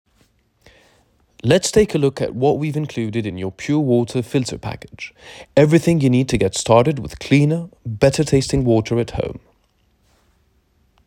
Accent anglais 2